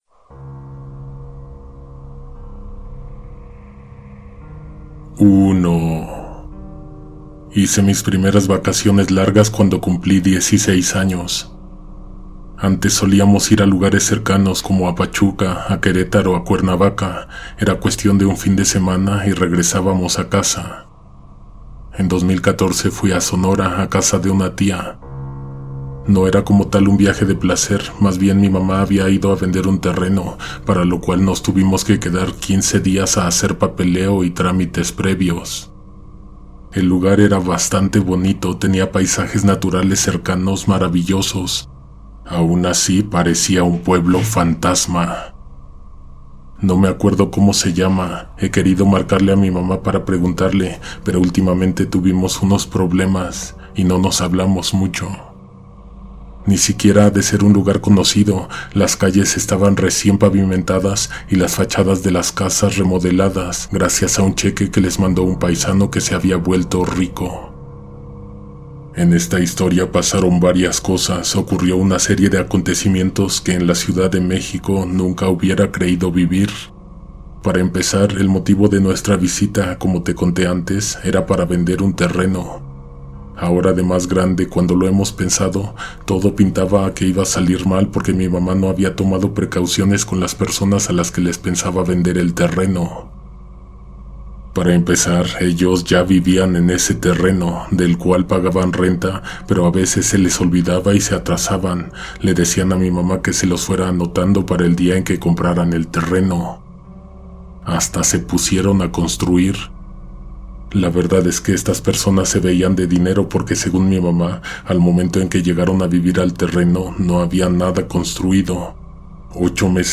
La Estatua Tras la Pared – Relato de Terror